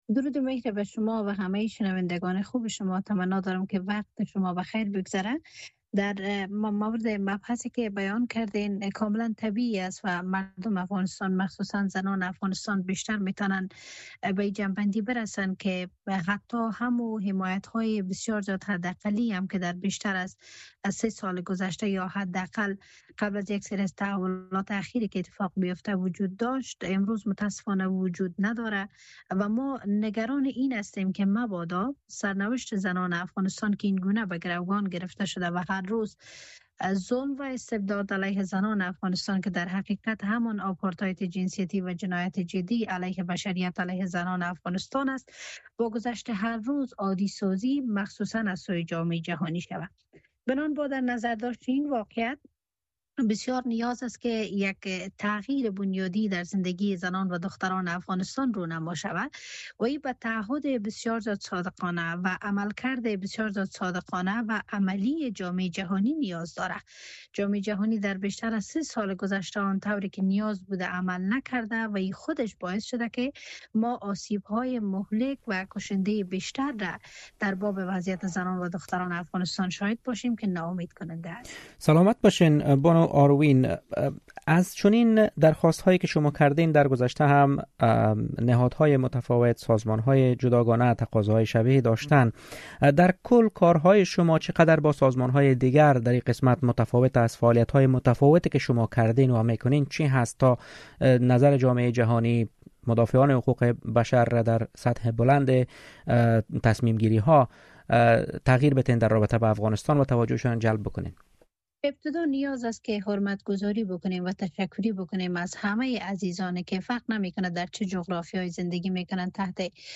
اهمیت حمایت جامعه جهانی از زنان و دختران در افغانستان؛ گفت‌وگو با یک عضو جنبش «شنبه‌های ارغوانی»
مصاحبه